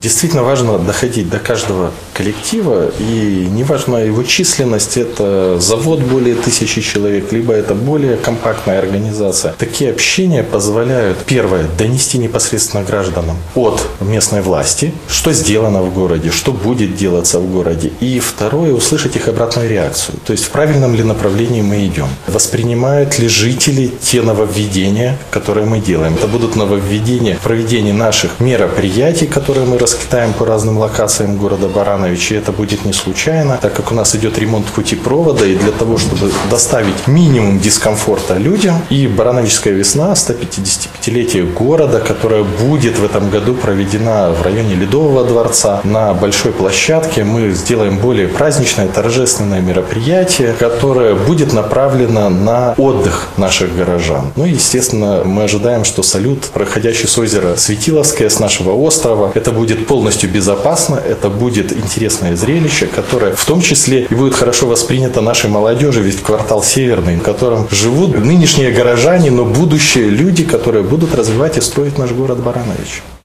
Затем прошла встреча трудового коллектива с председателем горисполкома.
antonyuk.-vstrecha.ogg